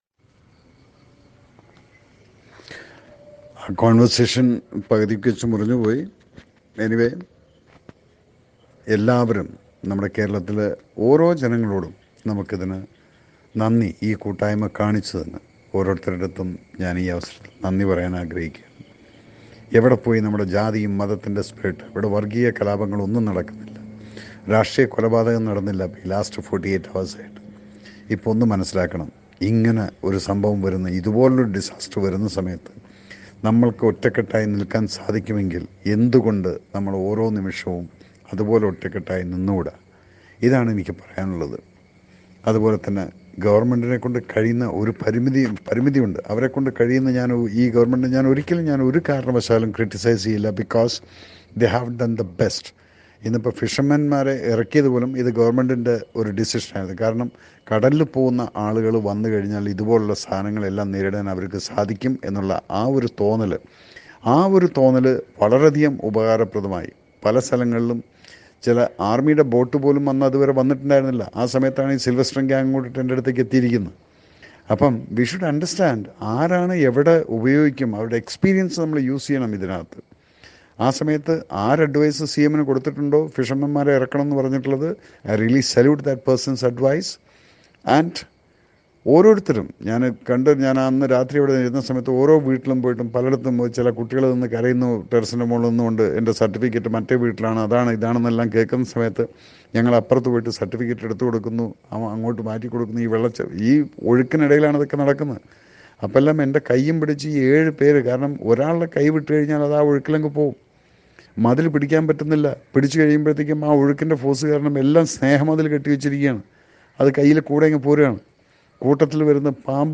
ജാതി മത വര്‍ഗ വ്യത്യാസങ്ങളില്ലാതെ മലയാളികള്‍ ഒറ്റക്കെട്ടായി നിന്നുവെന്ന് പറയുന്ന മേജര്‍ രവിയുടെ ഓഡിയോ ക്ലിപ്പ് സോഷ്യല്‍ മീഡിയയില്‍ വൈറലാകുന്നു.